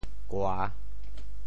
旰 部首拼音 部首 日 总笔划 7 部外笔划 3 普通话 gàn hàn 潮州发音 潮州 gua3 白 gang3 文 中文解释 旰〈名〉 (形声。